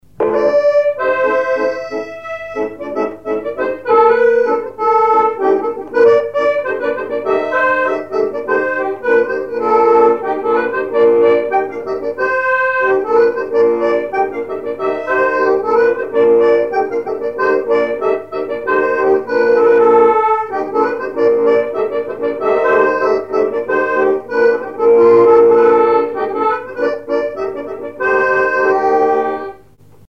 Mémoires et Patrimoines vivants - RaddO est une base de données d'archives iconographiques et sonores.
danse : scottich trois pas
instrumentaux à l'accordéon diatonique
Pièce musicale inédite